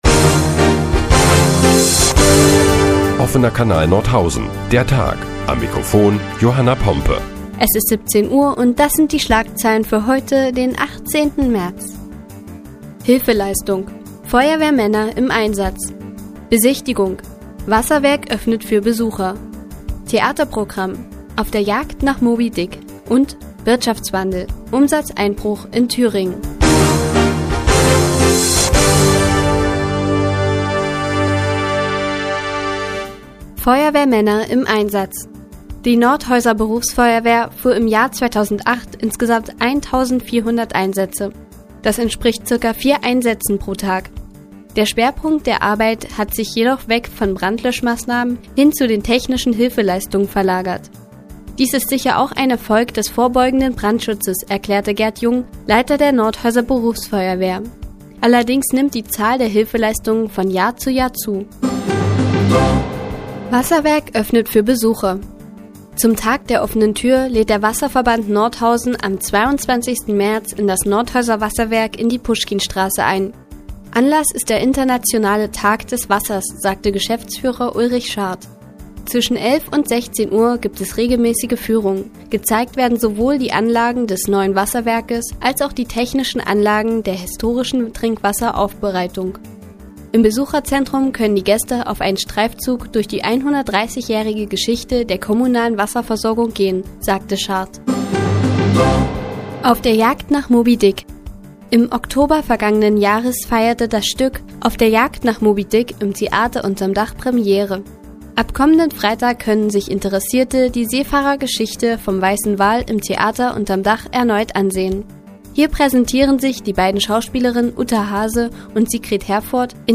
Die tägliche Nachrichtensendung des OKN ist nun auch in der nnz zu hören. Heute geht es unter anderem um die Hilfeleistungen der Feuerwehr und den Umsatzeinbruch in Thüringen.